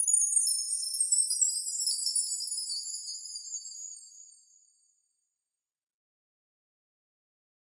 Окунитесь в мир необычных звуков: здесь собраны записи шорохов, оседания и движения пыли.
Пыль - Альтернативный вариант